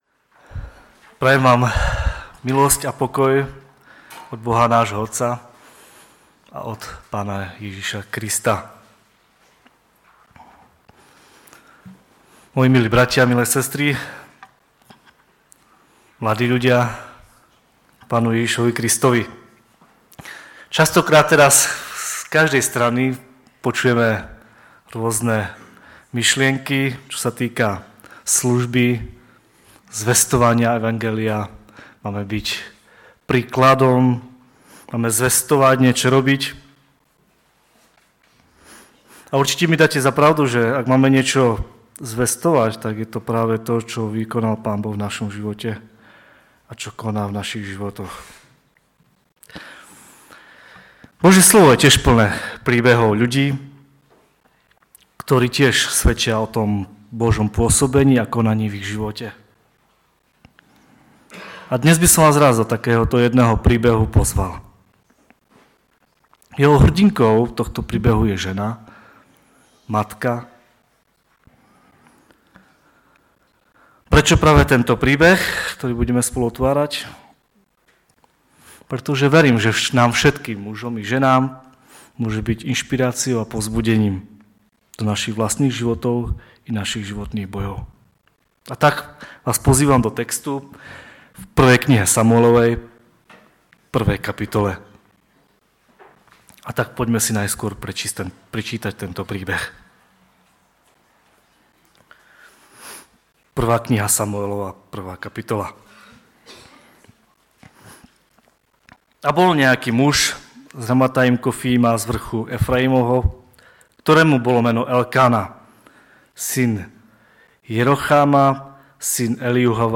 ve sboře Ostrava-Radvanice.
Kázání